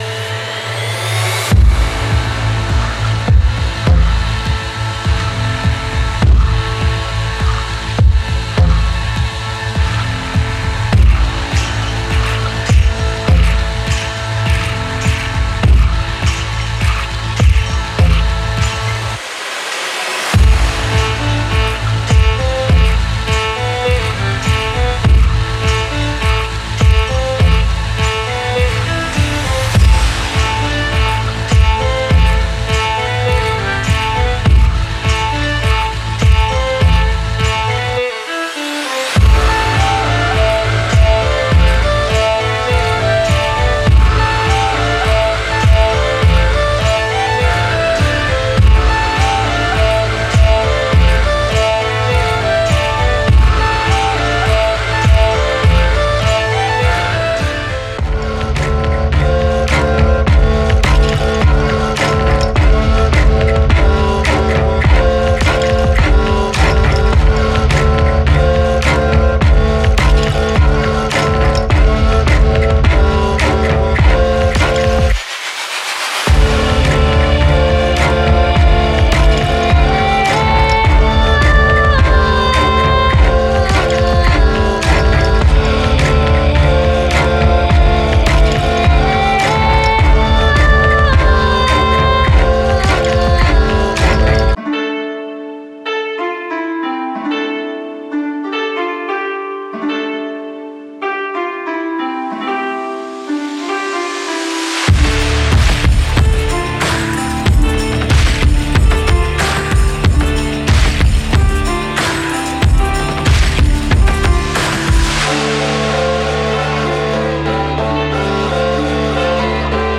Genre:Filmscore
デモサウンドはコチラ↓
66 Drum Part Loops
54 Melody Loops